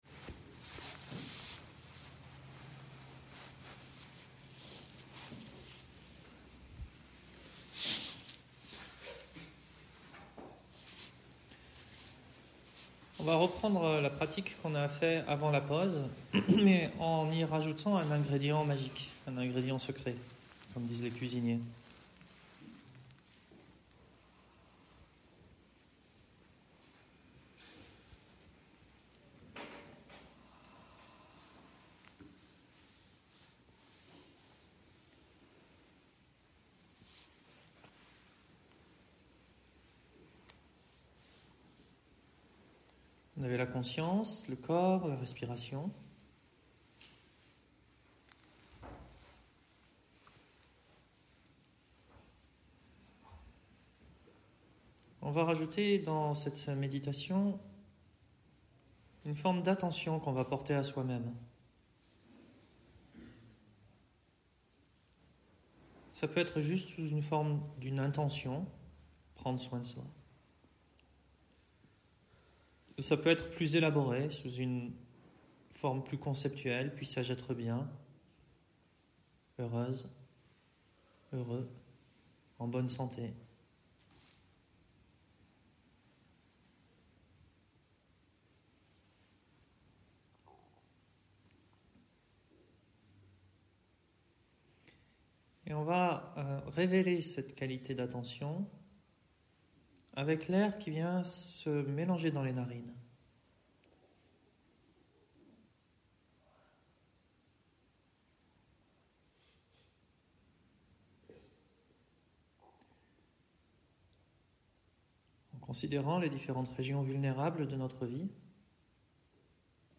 Auto compassion acceptation (live, stage de we)